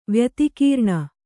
♪ vyatikīrṇa